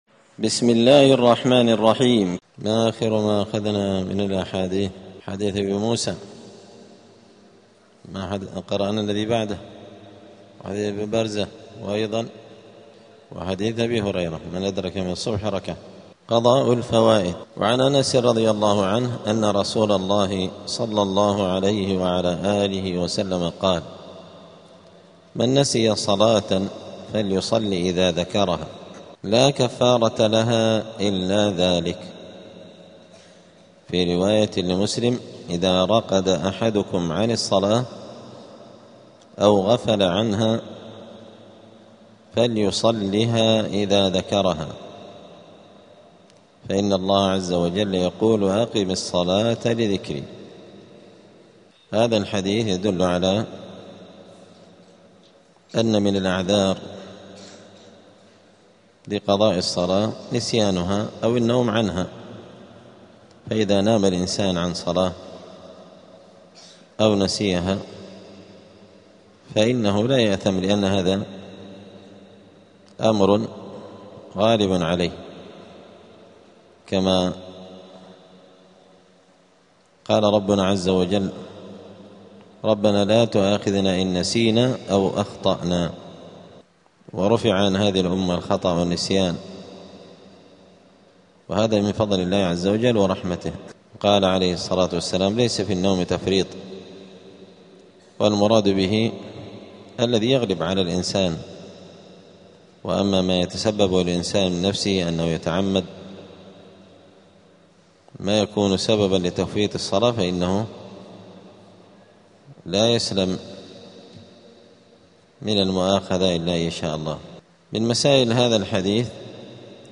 دار الحديث السلفية بمسجد الفرقان قشن المهرة اليمن
*الدرس الواحد والأربعون بعد المائة [141] {قضاء الفوائت}*